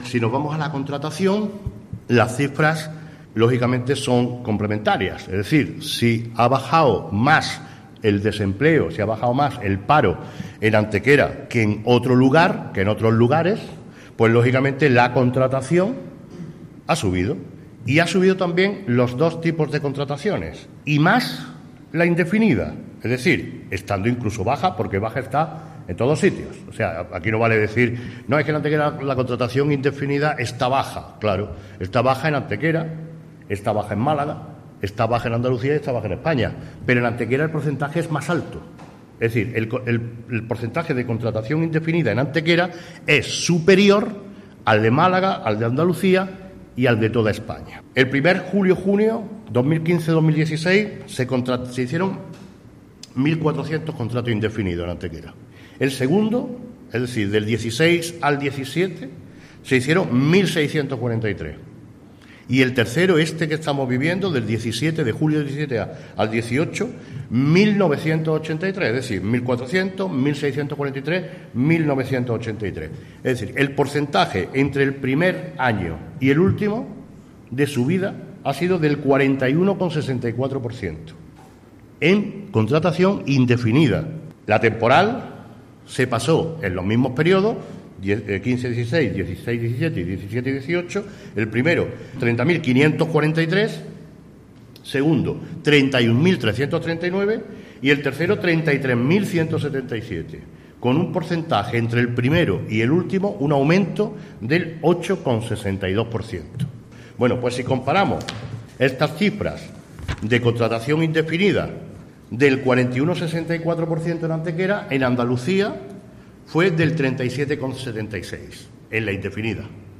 El alcalde de Antequera, Manolo Barón, y la teniente de alcalde Ana Cebrián han comparecido hoy en rueda de prensa para informar sobre la evolución de la contratación del desempleo en Antequera entre los meses de julio de los años 2015 y 2018, coincidiendo por tanto con el inicio del actual mandato.
Cortes de voz